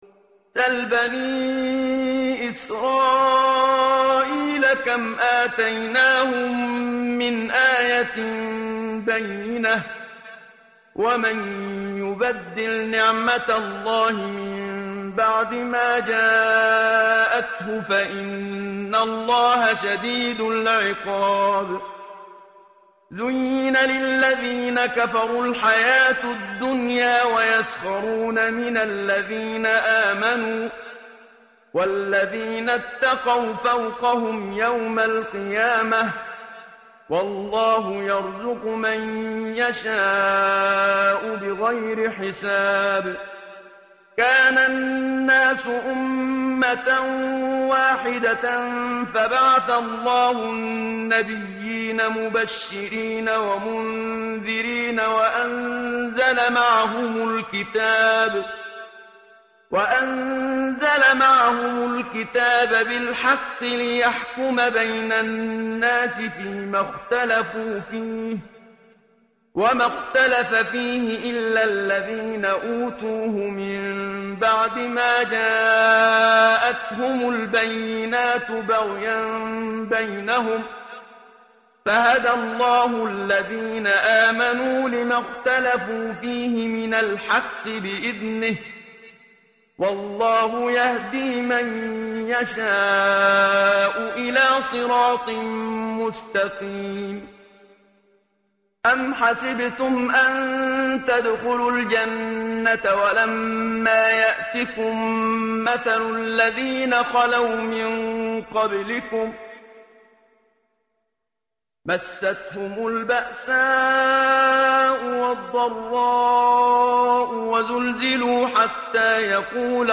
متن قرآن همراه باتلاوت قرآن و ترجمه
tartil_menshavi_page_033.mp3